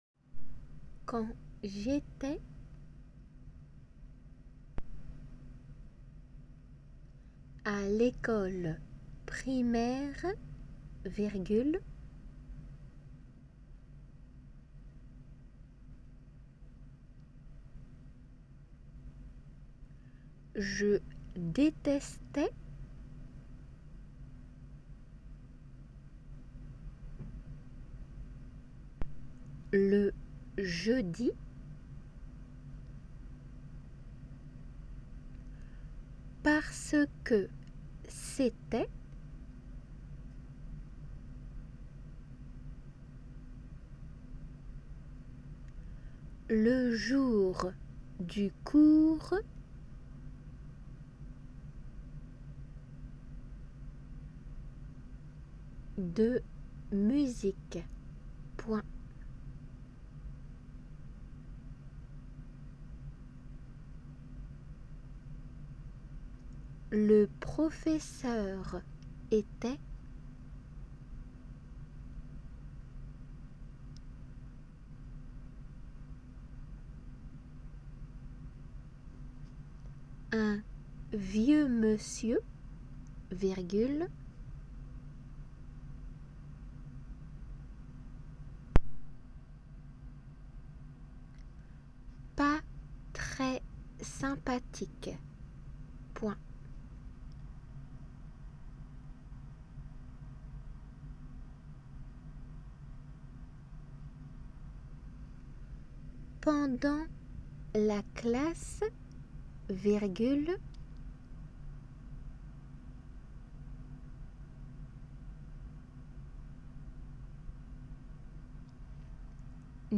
仏検　2級　デイクテ　音声ー秋２
練習用　です。実際の仏検では普通の速さで2回読まれ
3回目にデイクテの速さで読まれます。